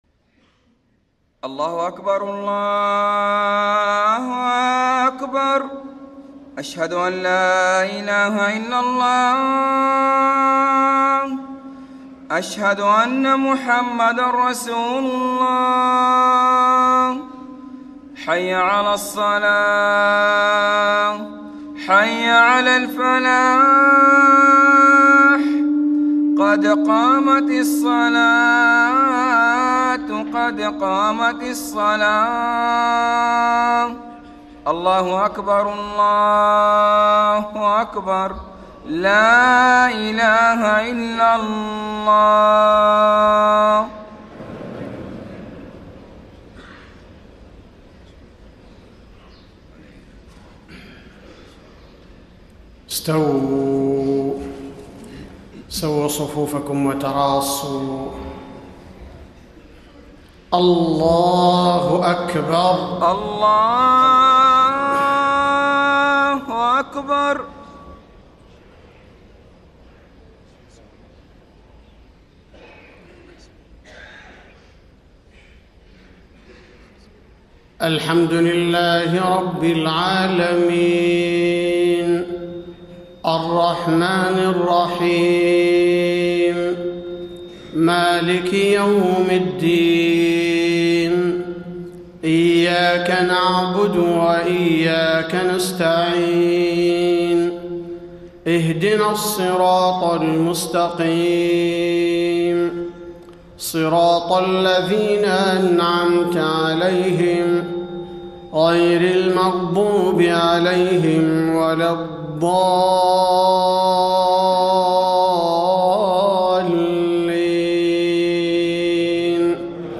صلاة الفجر 4-5-1434 من سورة البقرة > 1434 🕌 > الفروض - تلاوات الحرمين